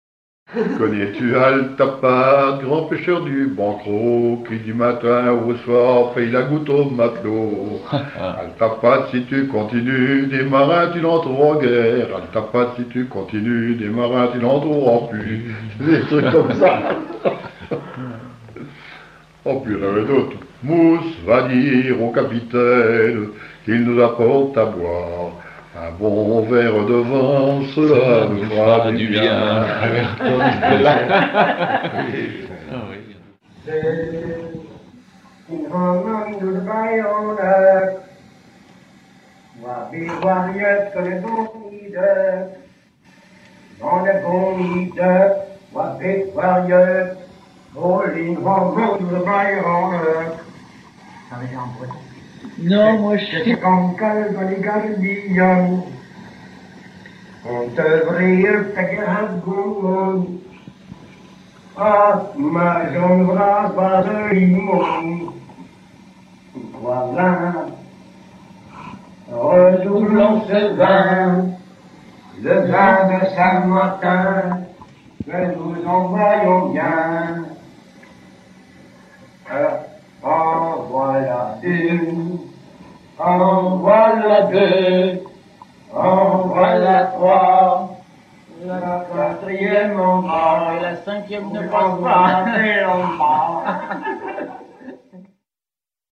Haleurs ou dameurs travaillant ensemble rythmiquement
circonstance : maritimes
Pièce musicale éditée